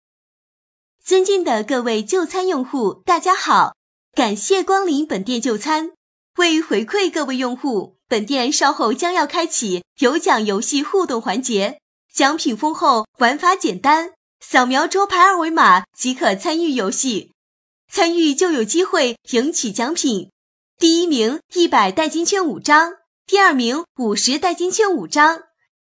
【女23号广告】小猫渔捞活动
【女23号广告】小猫渔捞活动.mp3